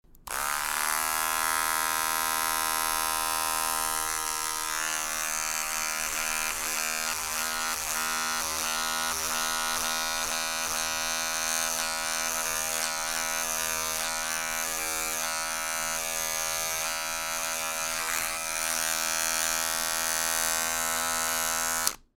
Триммер для волос 5